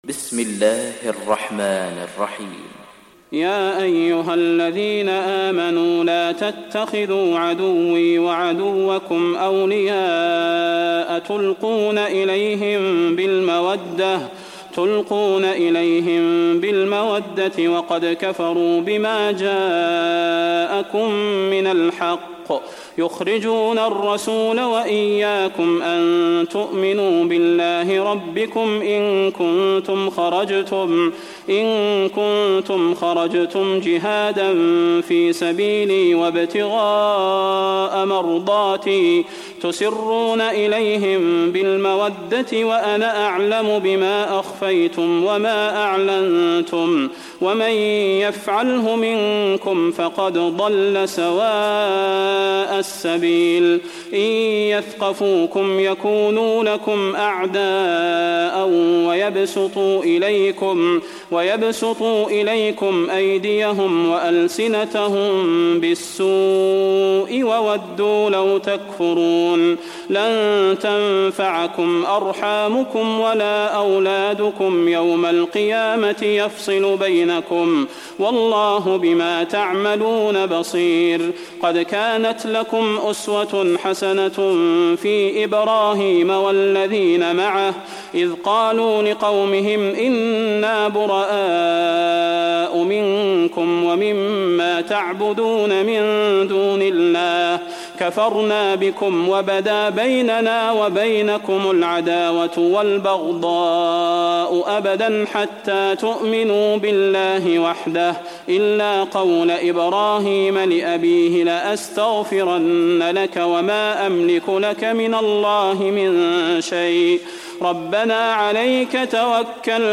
تحميل سورة الممتحنة mp3 بصوت صلاح البدير برواية حفص عن عاصم, تحميل استماع القرآن الكريم على الجوال mp3 كاملا بروابط مباشرة وسريعة